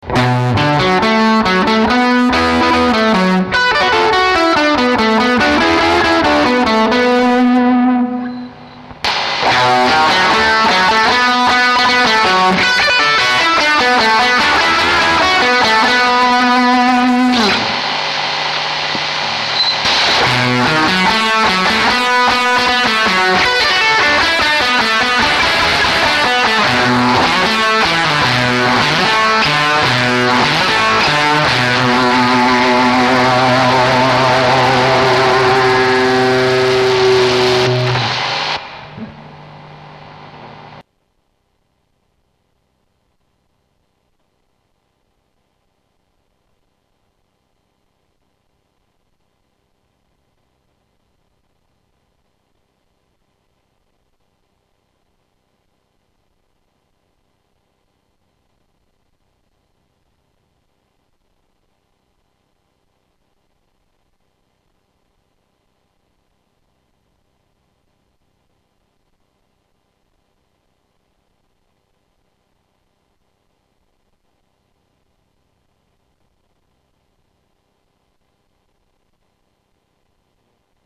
Samples de la fuzzbrite.
Ca dégomme, je suis vraiment fan, c'est excellent...!!